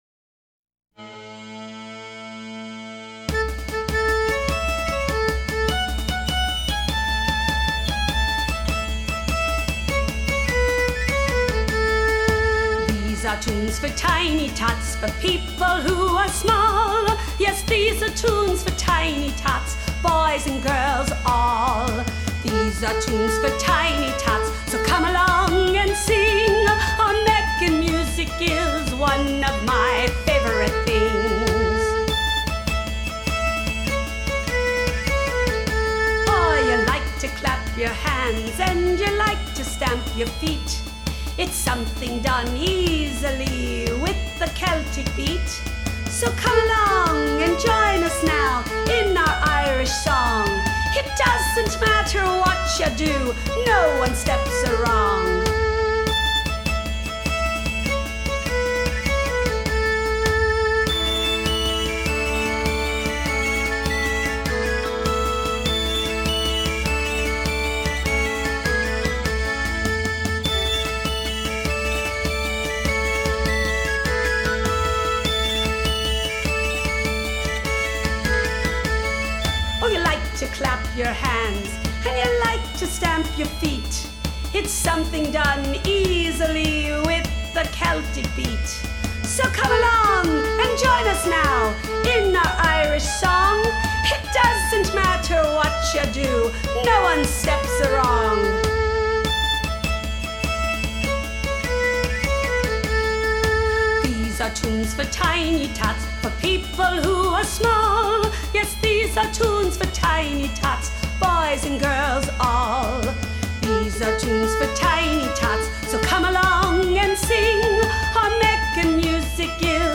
Genre: Alternative & Punk.